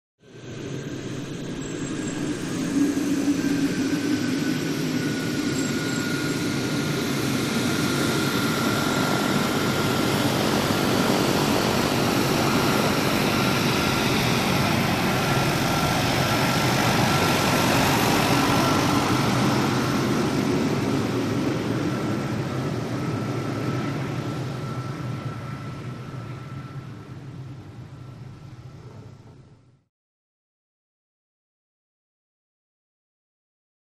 Twin-engine Turboprop Plane Taxi By Right To Left.